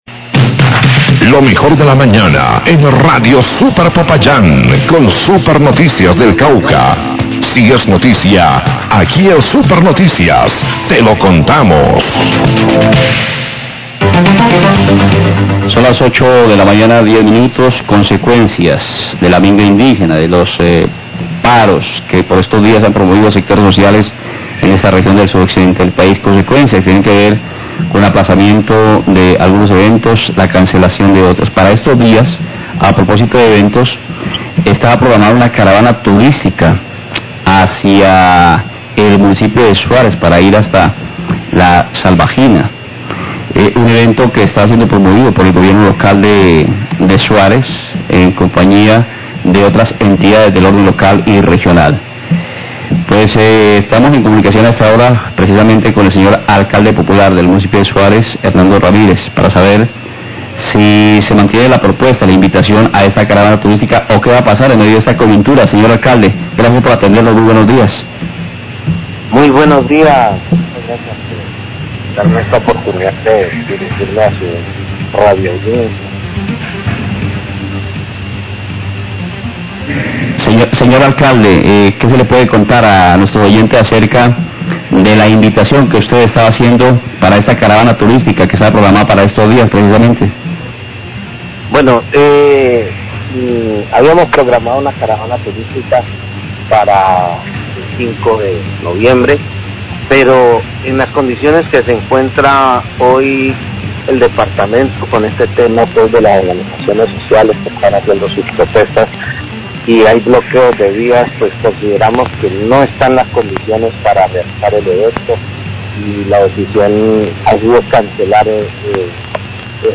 Radio
Alcalde de Suárez, Hernando Ramírez, anuncia que la caravana turística a Suárez y La Salvajina que estaba programada para el próximo 5 de noviembre fue aplazada hasta nueva orden debido a que no hay condiciones de seguridad por la minga indígena qe se desarrolla en vias del Cauca.